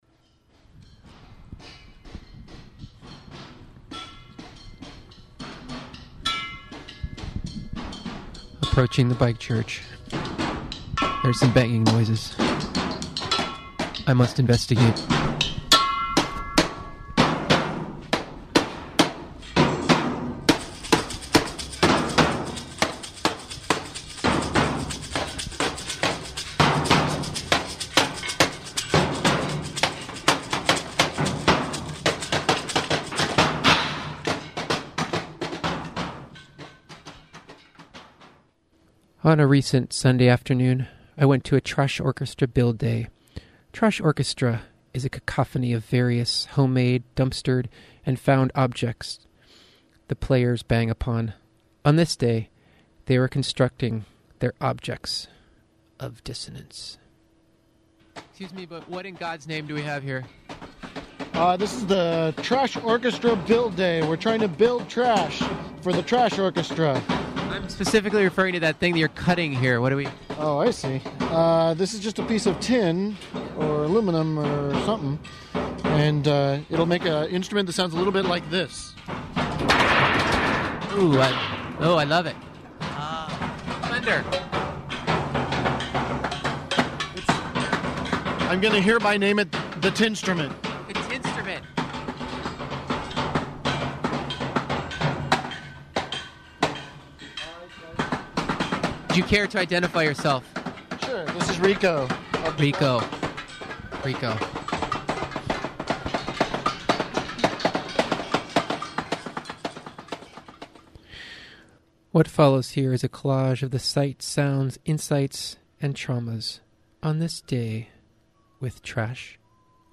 Using recycled food cans, hubcaps, barrels, sheet metal, and other discarded oddities, the Santa Cruz Trash Orchestra is a bone-shaking, recycled ensemble – a marching percussion band - who show up invited or otherwise, to make a cacophony of noise and celebration at parties and protests. Trash Orchestra players held an instrument building workshop as a Free Skool class in early December, 2006.